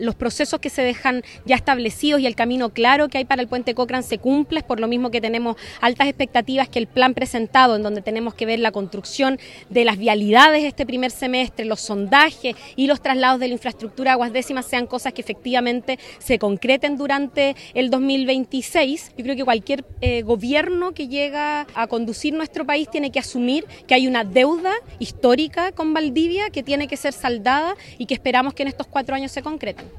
Consultada por Radio Bío Bío, la jefa comunal mencionó que existe una deuda histórica con Valdivia, por lo que envió un mensaje al próximo Gobierno de José Antonio Kast para continuar con los avances que deja la administración de Gabriel Boric, en especial en la construcción del puente Cochrane.